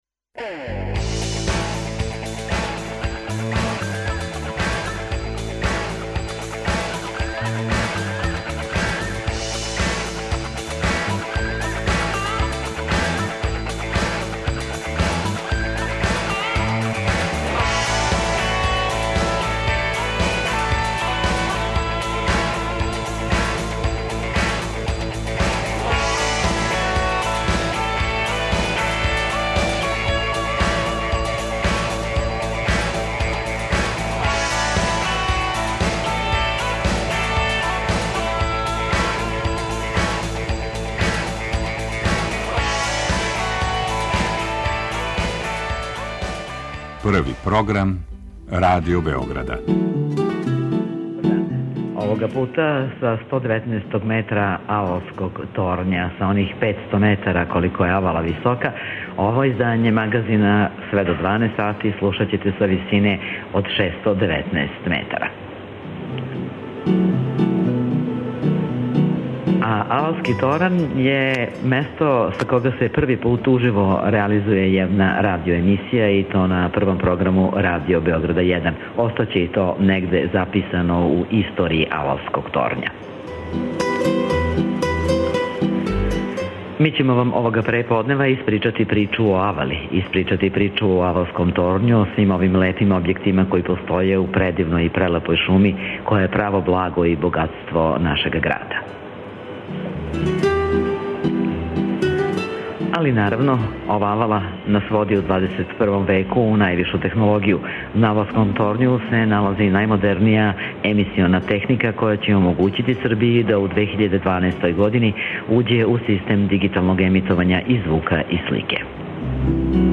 Данашња емисија биће емитована са 120м, Авалског торња, највишег торња у региону опремљеног најмодернијом емисионом техником.
Подсетићемо се овог архитектонског ремек дела на три носећа студија, открити будућност дигитализације нашег медијског неба. Биће ово прва радијска емисија емитована са новог Авалског торња.